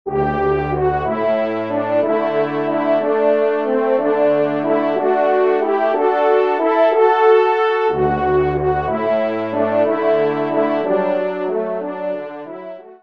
20 sonneries pour Cors et Trompes de chasse